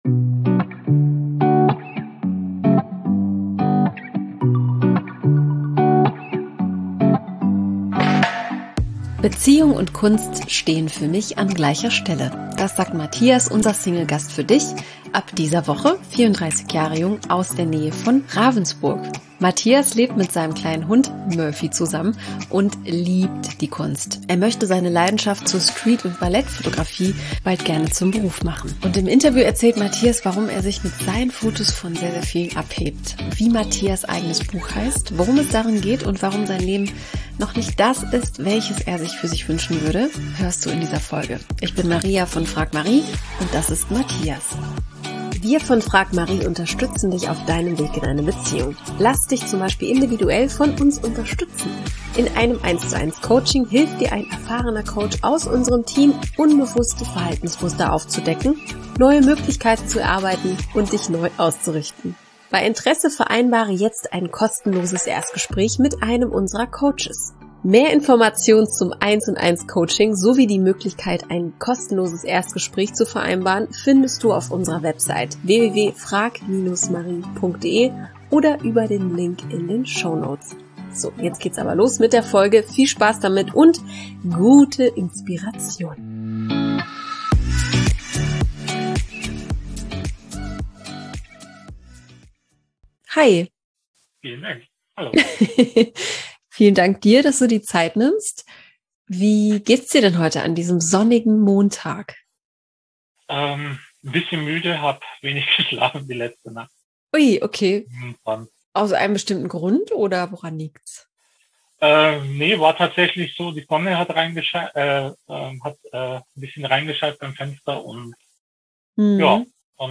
Im Podcast zum Verlieben interviewen wir für dich Singles zum Kennenlernen. Wir lassen Menschen in einem lockeren und inspirierenden Gespräch zu Wort kommen, die offen für die Liebe sind.